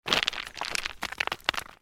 دانلود آهنگ کوه 5 از افکت صوتی طبیعت و محیط
جلوه های صوتی
دانلود صدای کوه 5 از ساعد نیوز با لینک مستقیم و کیفیت بالا